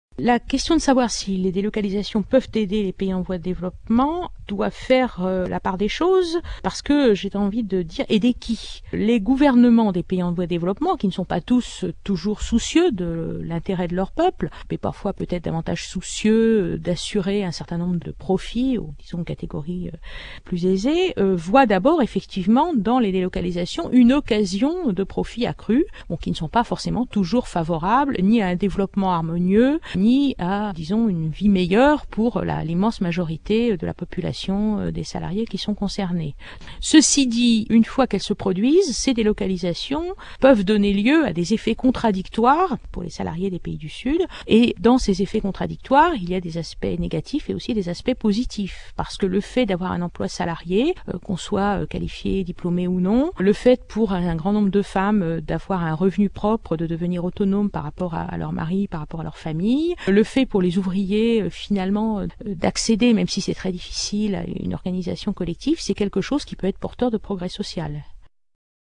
Cet interview a été réalisé à l’issue de la projection du documentaire de Marie France Collard, Ouvrières du monde, (Belgique, 2000. 52’).